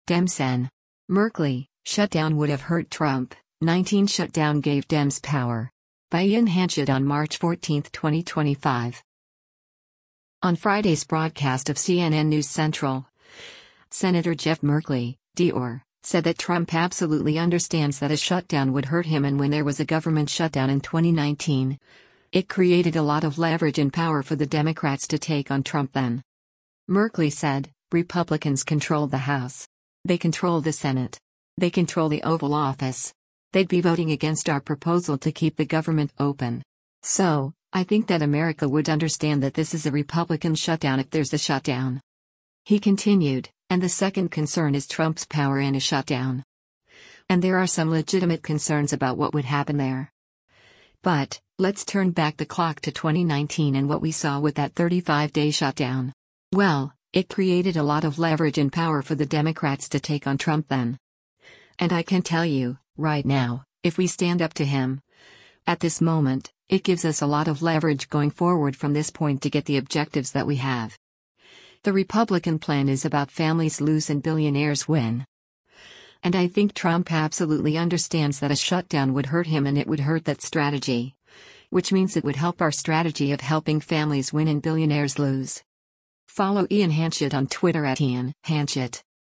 On Friday’s broadcast of “CNN News Central,” Sen. Jeff Merkley (D-OR) said that “Trump absolutely understands that a shutdown would hurt him” and when there was a government shutdown in 2019, “it created a lot of leverage and power for the Democrats to take on Trump then.”